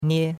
nie1.mp3